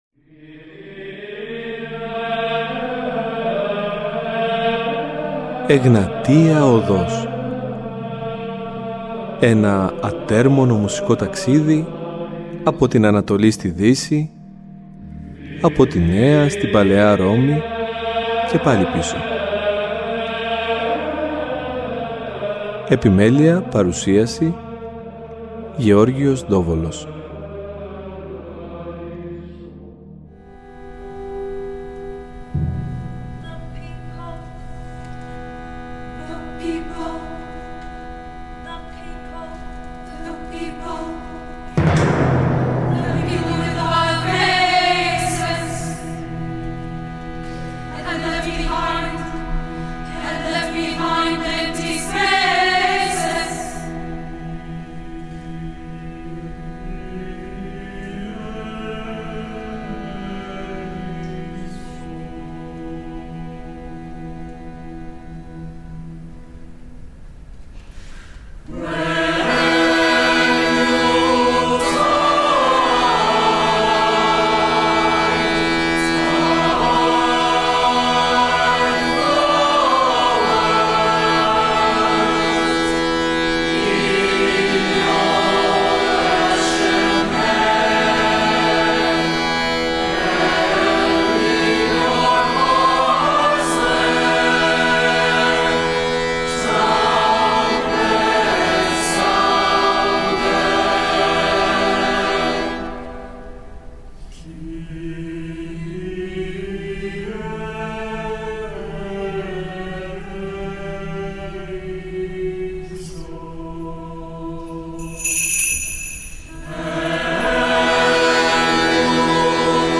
Στα πλαίσια του δίπτυχου αφιερώματός μας θα έχουμε την ευκαιρία να τον γνωρίσουμε καλύτερα μέσα από την συνέντευξη που παραχώρησε στην Εγνατία Οδό και παράλληλα να εστιάσουμε στην εκκλησιαστική μουσική ως μία βασική πηγή έμπνευσής του .